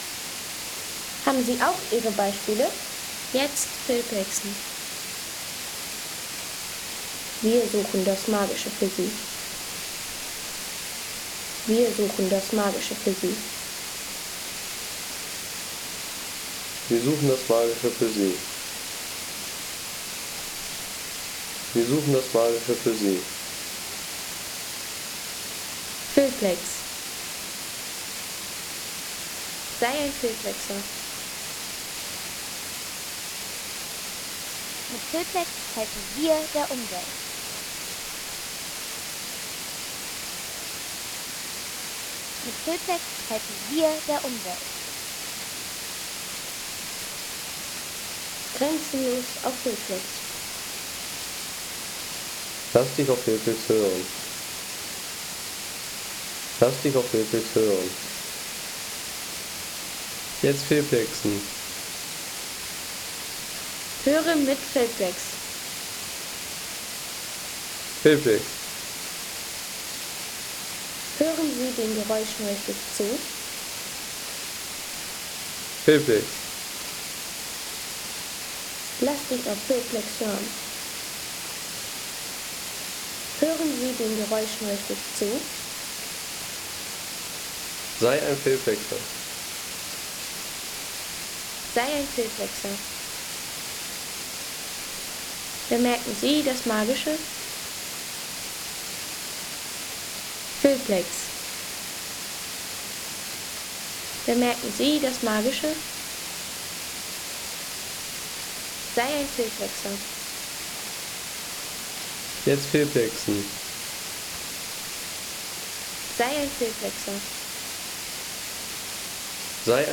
Letzter Fall Gesäuse – Wasserfallaufnahme mit Salzatalblick
Kraftvolle Klangaufnahme direkt am letzten Wasserfall zum Palfauer Wasserloch – mit herrlicher Aussicht ins Salzatal.
Direkt am letzten Wasserfall vor dem Palfauer Wasserloch – erleben Sie hautnahes Wasserrauschen und die Aussicht ins Salzatal in dieser naturgetreuen Tonaufnahme.